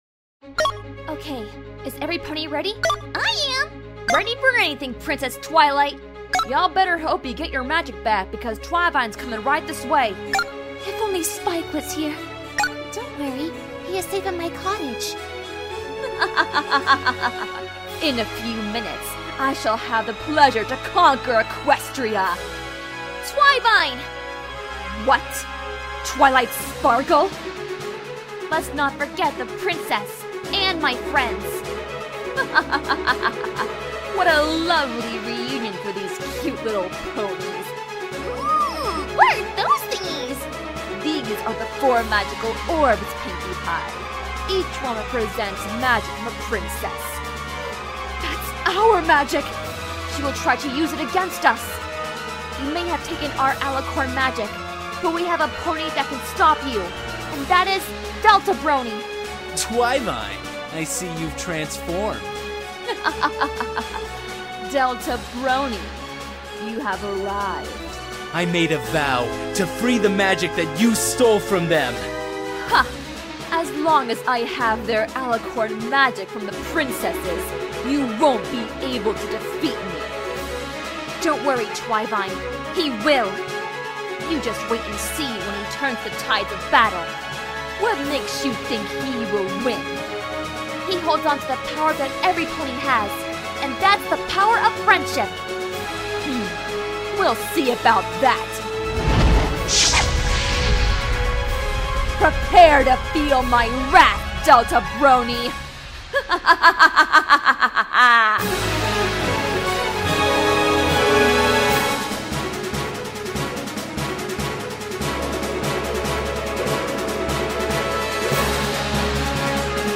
genre:soundtrack